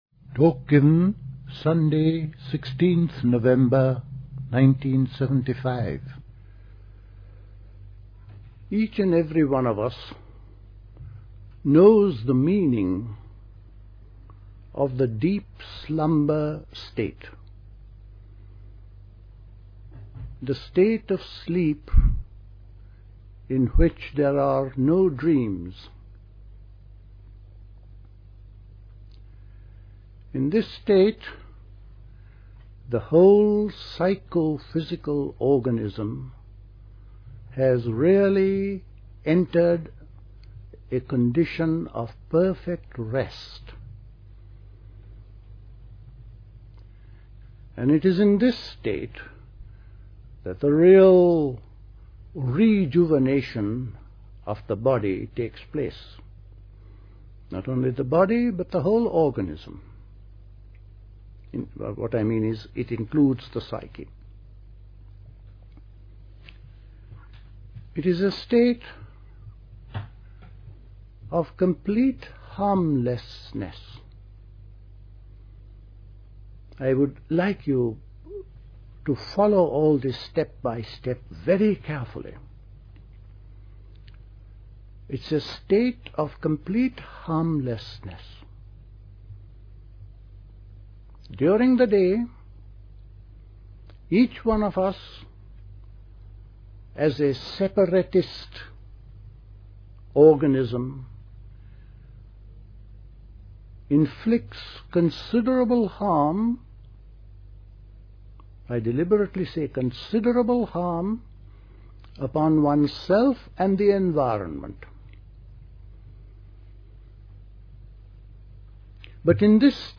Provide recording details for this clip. at Dilkusha, Forest Hill, London